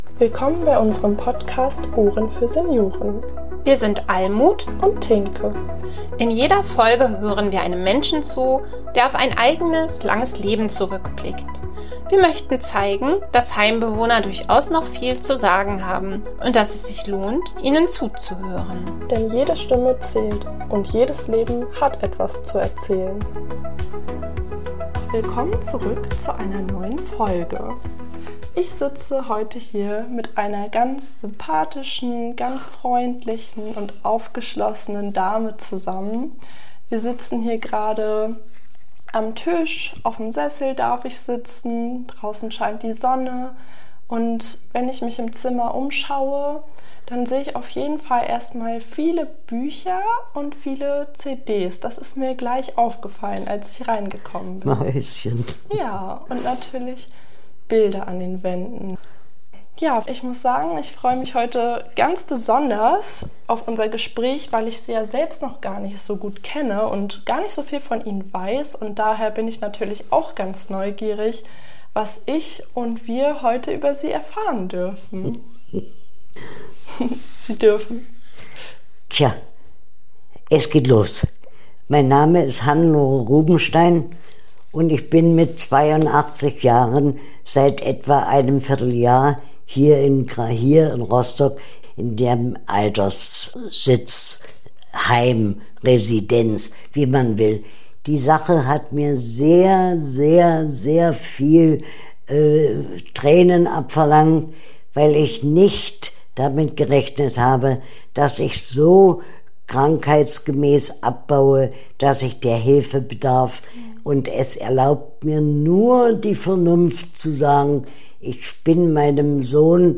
In dieser Folge begegnen wir einer unglaublich klugen, lebenserfahrenen Frau, die mit einer solchen Kraft und Schönheit erzählt, dass die Welt für einen Moment stillzustehen scheint.